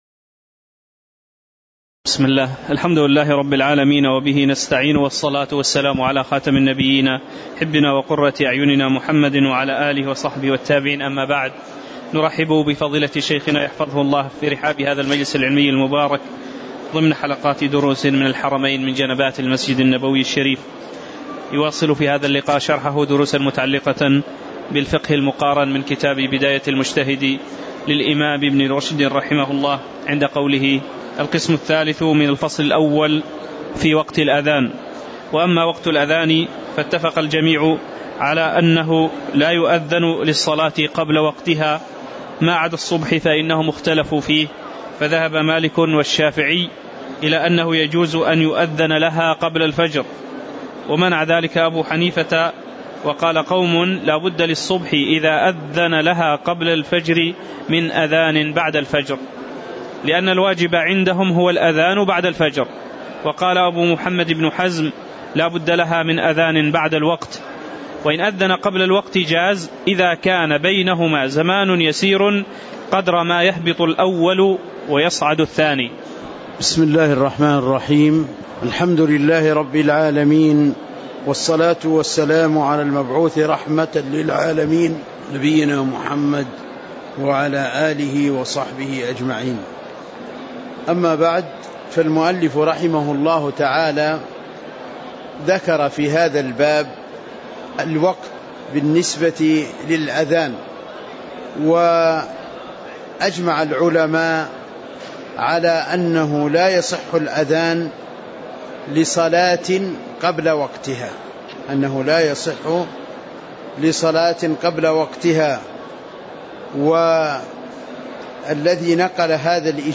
تاريخ النشر ٢٩ صفر ١٤٤١ هـ المكان: المسجد النبوي الشيخ